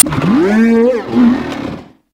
Grito de Cyclizar.ogg
) Categoría:Ciclyzar Categoría:Gritos de Pokémon de la novena generación No puedes sobrescribir este archivo.
Grito_de_Cyclizar.ogg.mp3